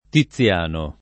tiZZL#no] pers. m. — non il T., ma T. assol.